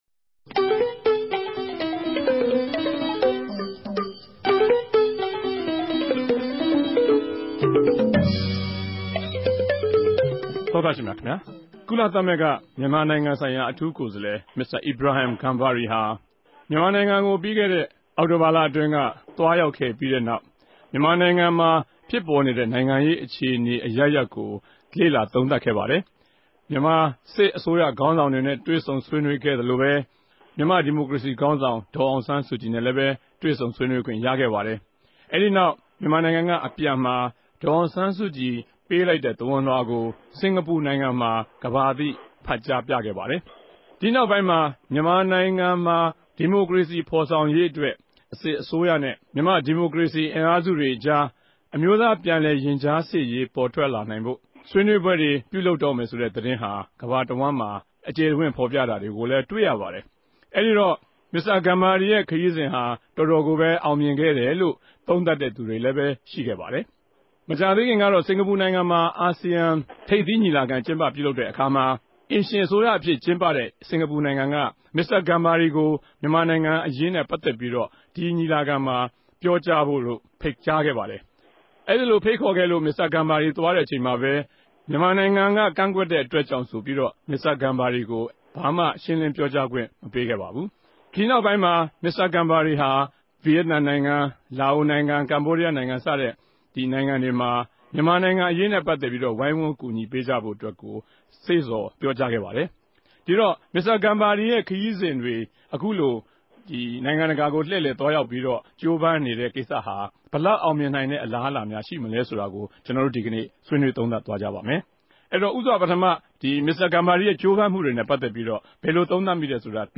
စကားဝိုင်း